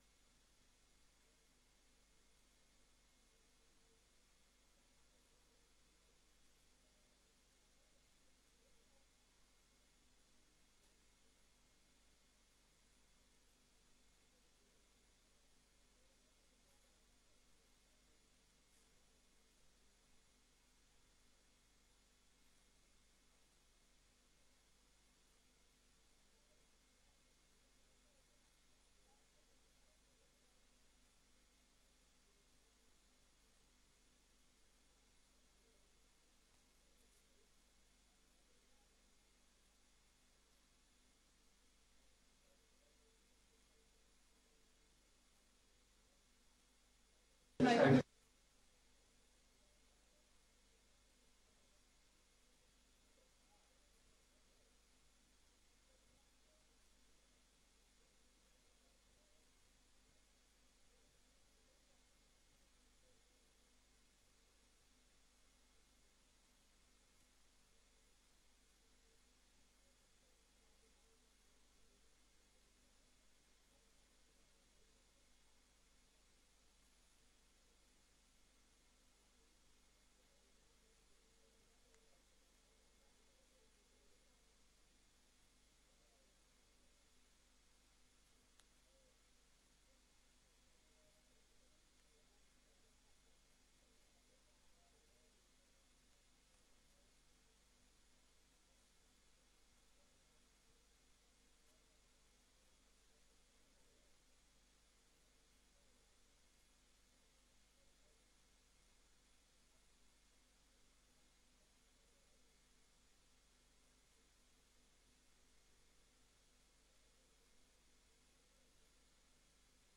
Raadsvergadering Papendrecht 11 juli 2024 20:00:00, Gemeente Papendrecht
Locatie: Raadzaal